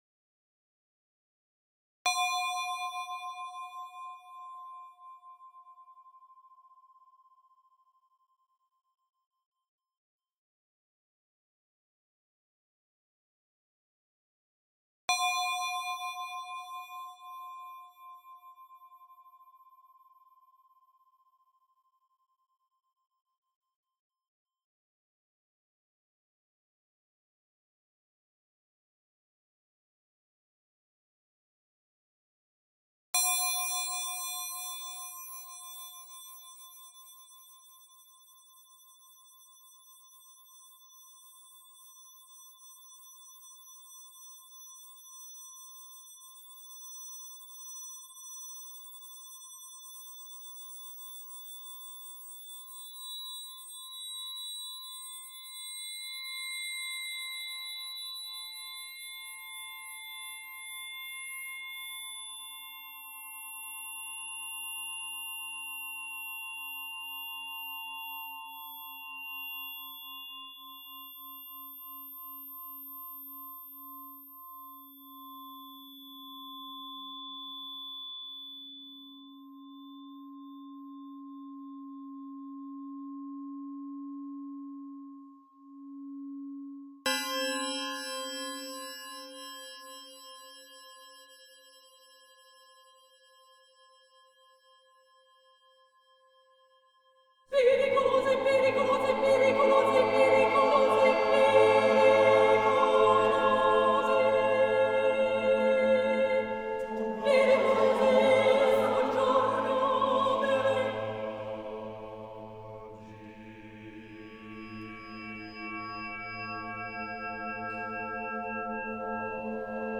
concert recording
soprano